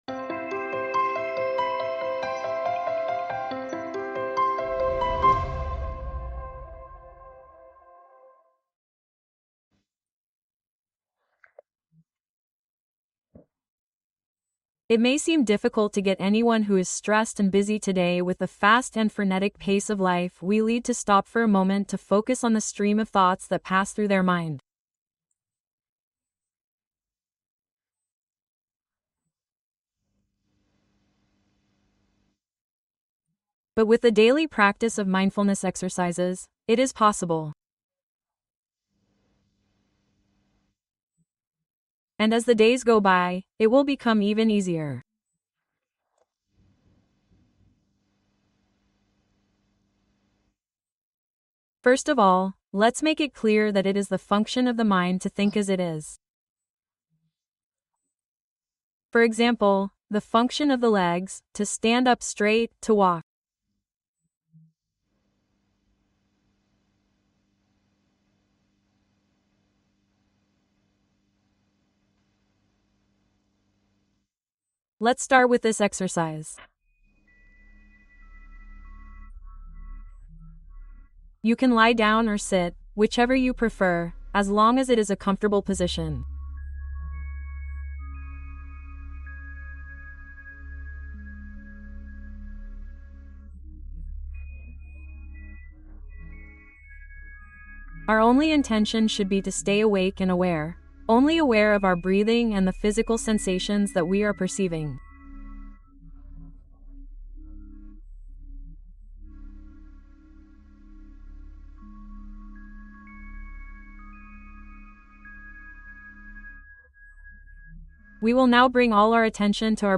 Atención Plena Emocional: Meditación para Reconocer y Regular Emociones